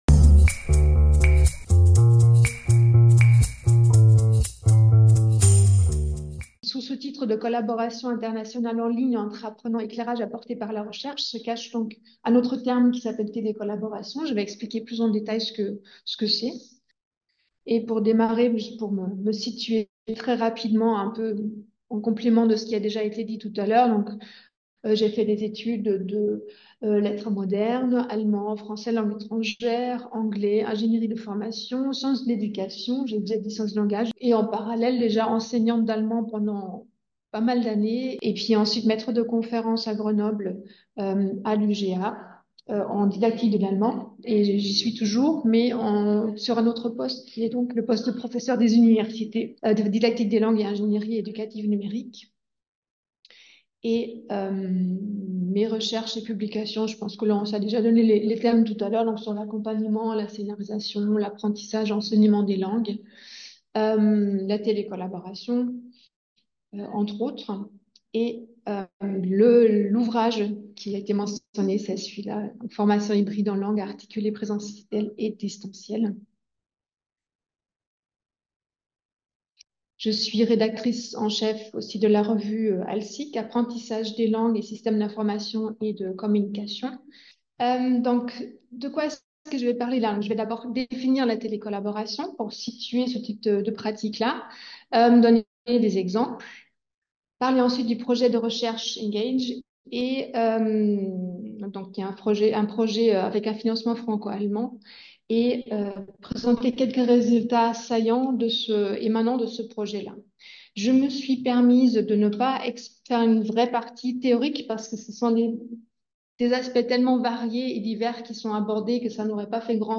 [Conférence]
Cette communication s'est tenue à l'université Lumière Lyon 2 le 10 avril 2024, dans le cadre du séminaire «Schwerpunkt Deutschland» organisé par le pôle rhône-alpin du CIERA (Centre interdisciplinaire d'études et de recherches sur l'Allemagne), qui réunit l'université Lumière Lyon 2, l'ENS de Lyon et Sciences Po Grenoble.